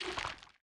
Minecraft Version Minecraft Version latest Latest Release | Latest Snapshot latest / assets / minecraft / sounds / block / sculk / spread1.ogg Compare With Compare With Latest Release | Latest Snapshot